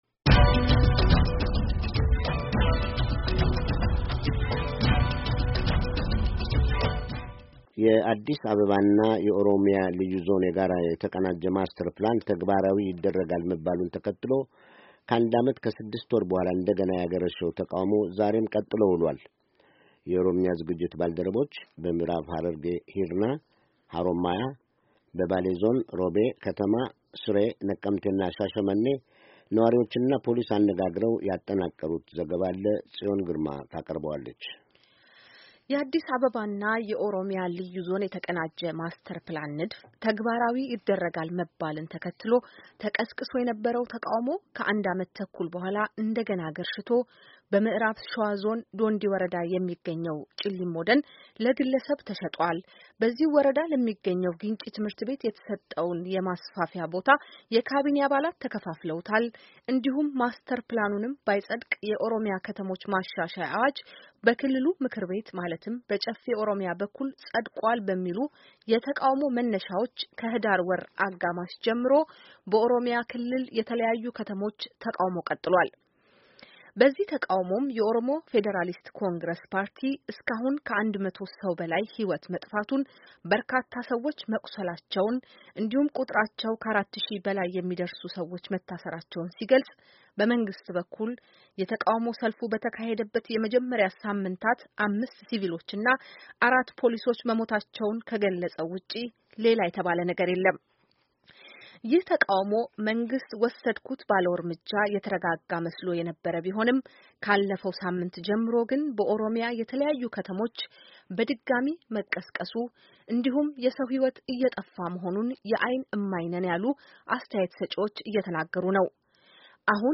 የኦሮምኛ ዝግጅት ባልደረቦች በምእራብ ሃረርጌ ሂርና፣ ሃሮማያ፣ በባሌ ዞን ሮቤ ከተማ ስሬ፣ ነቀምቴ እና ሻሸመኔ ነዋሪዎችንና ፖሊስ አነጋግረው ያጠናቀሩት ዘገባ